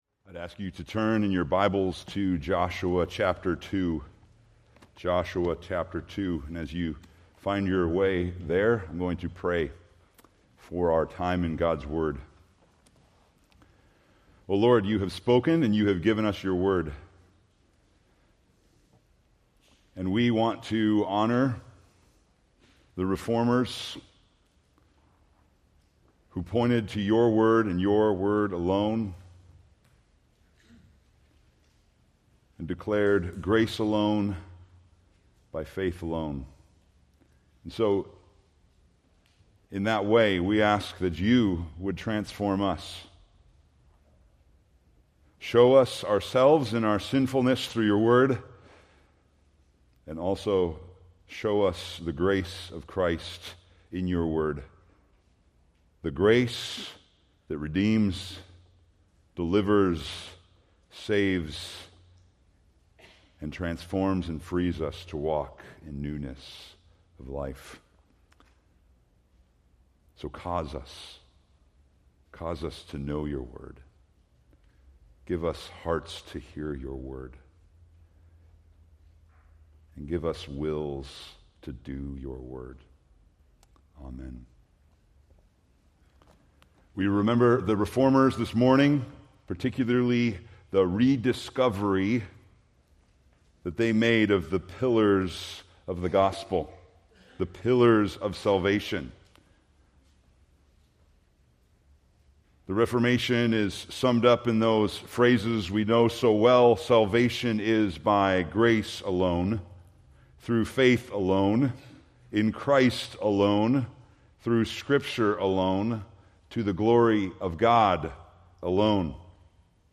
Preached October 26, 2025 from Joshua 2